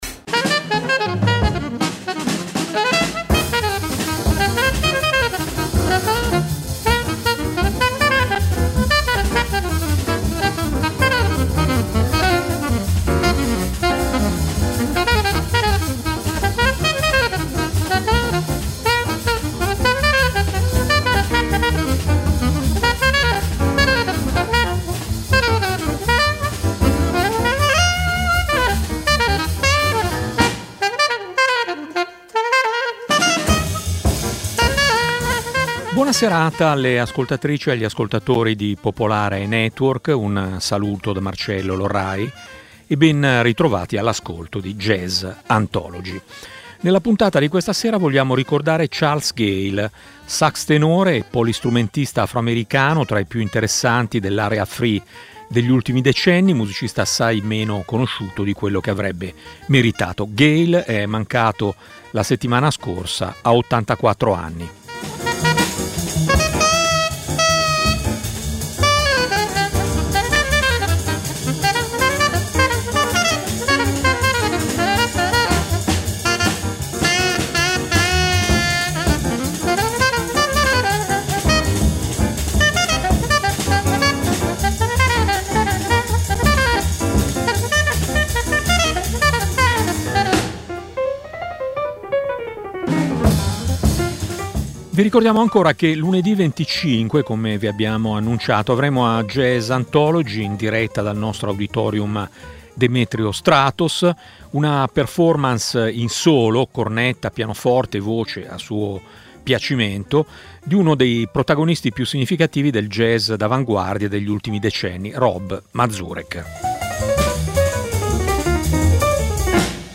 jazz d'avanguardia
facendo una musica senza compromessi, completamente libera
sax tenore
energetiche improvvisazioni